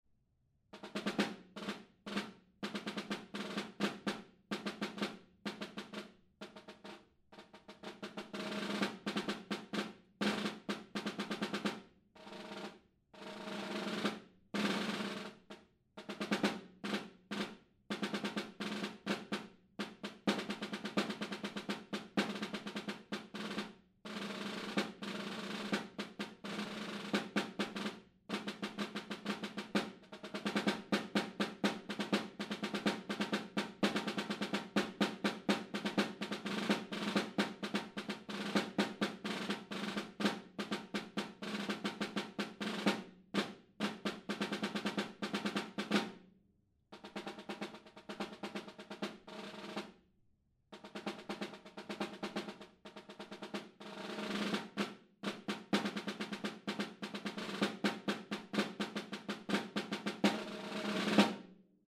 Encore 2026 Snare Drum Solos
Demo